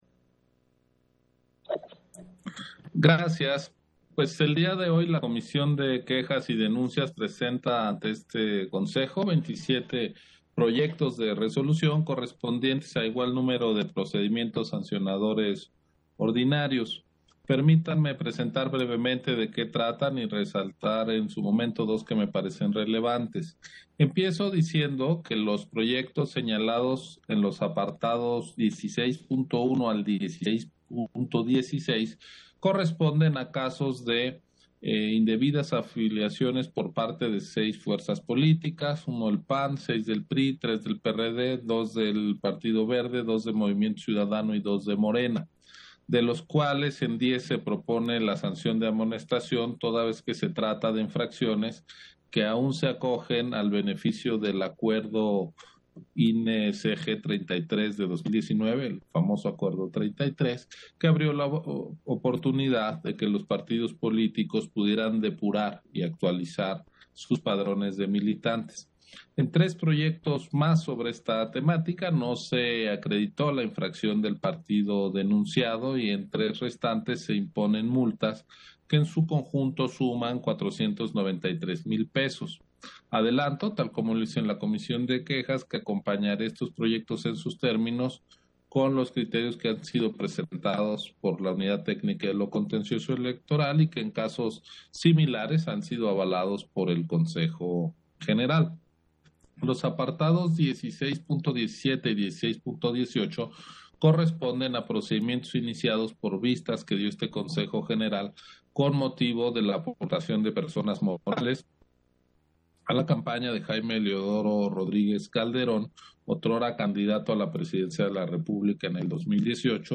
Intervención de Ciro Murayama en Sesión Ordinaria, en el punto relativo a diversas quejas por hechos que se considera constituyen infracciones a la ley en la materia